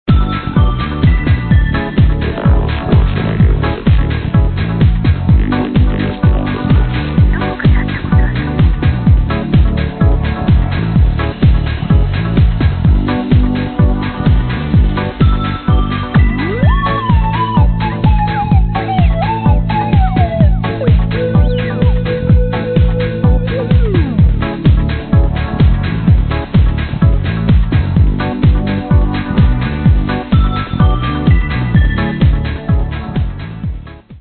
楽しそうで少し不安な様子を、909ローランドのリズムボックスのリズムに乗せ
後半では上もの(打ち込みのKEYBOARD)をRepeatさせながら
BASS LINEだけであらゆる景色を作ってみました。
Bass